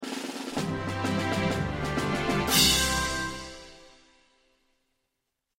Звуки победы, салюта
Уровень завершен